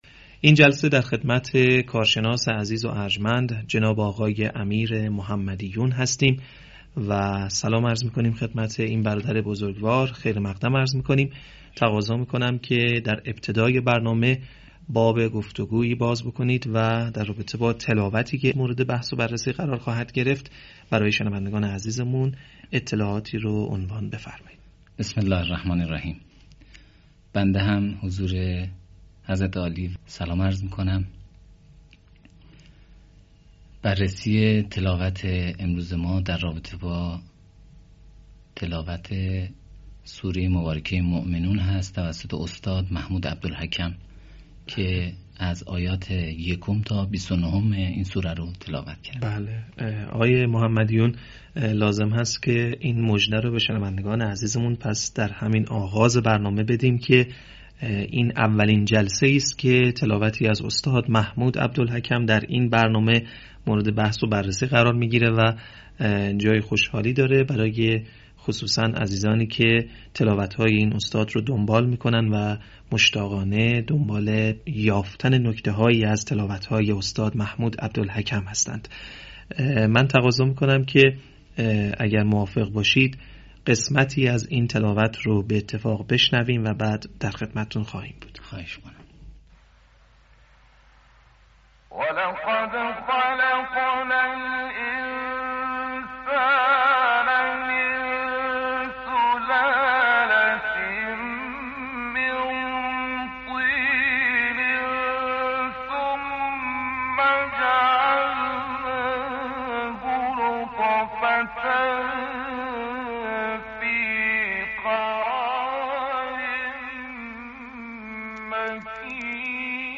تحلیل تلاوت محمود عبدالحکم